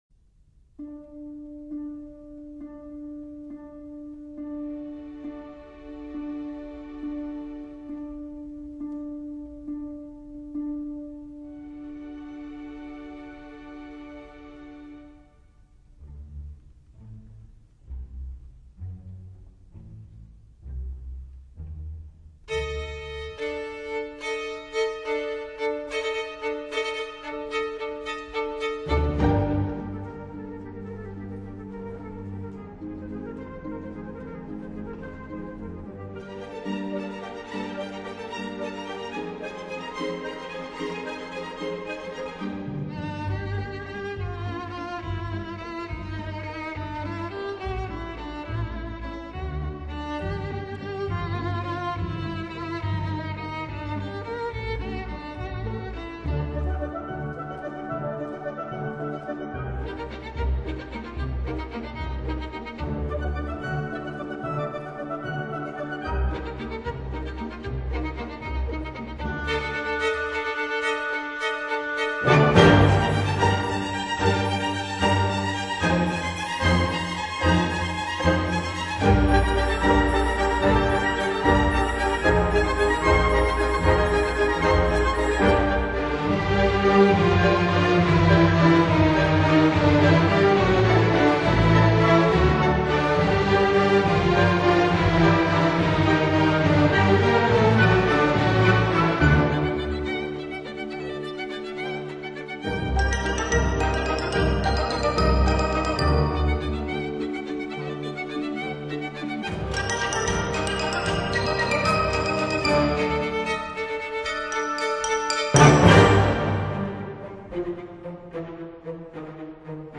不仅没有幽怨、恐怖的感觉，还焕发出一种热闹、激扬的舞会气氛，使人百听不厌！
需要留意的是此碟电平极低，因此最强音与最弱音之间的动态对比非常明显，给重播器材带来严峻考验。
突然，独奏小提琴奏出圆舞曲的节奏，描写死神的骷髅互相击碰着出现了。
整部作品具有神秘、恐怖的特色。